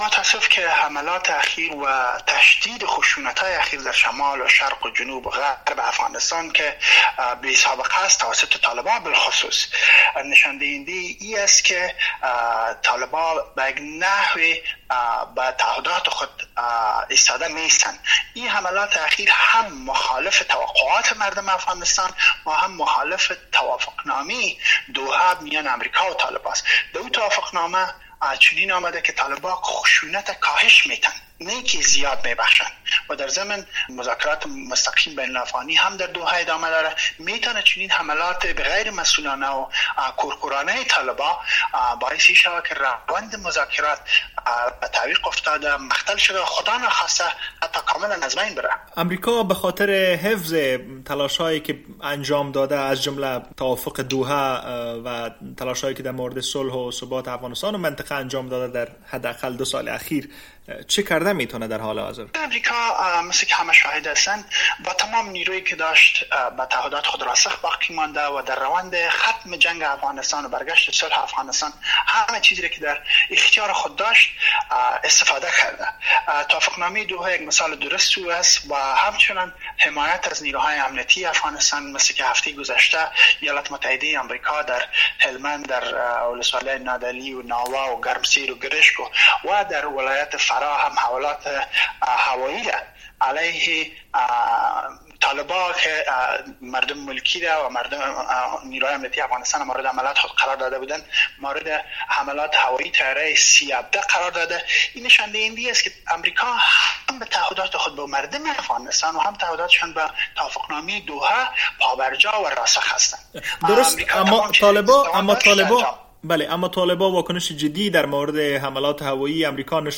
این مشاور پیشین ناتو در یک مصاحبه از واشنگتن با رادیو آزادی افزود که طالبان در مناطق مختلف حمله‌های‌شان را تشدید بخشیده‌اند....